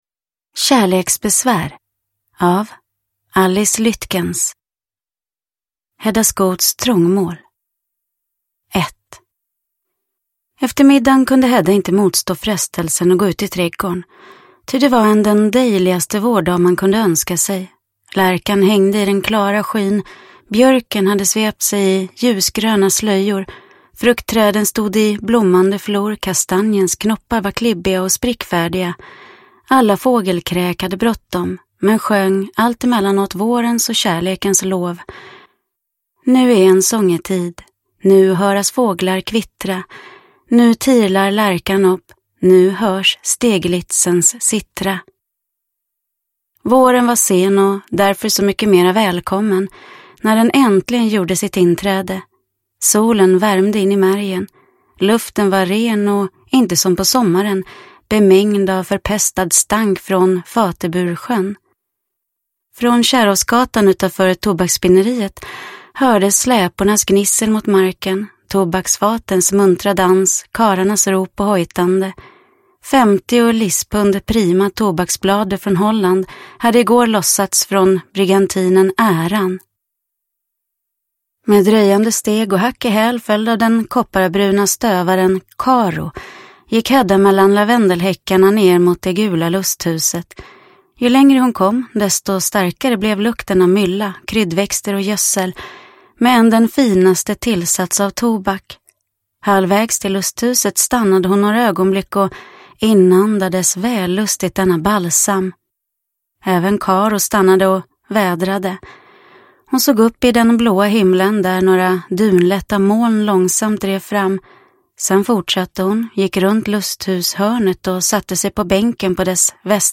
Kärleksbesvär – Ljudbok – Laddas ner